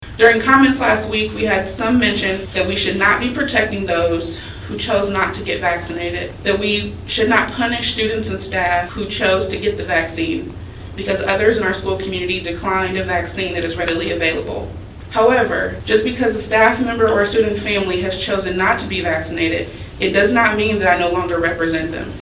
The board held a special meeting Wednesday night to adopt the plan before the start of classes.